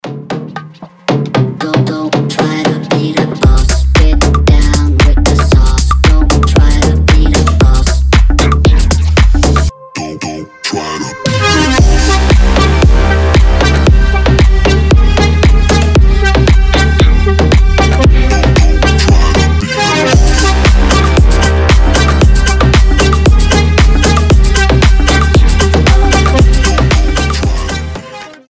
• Качество: 320, Stereo
EDM
Bass House
качающие
electro house
электронные
Интересный звонок на босса с забавным электронным голосом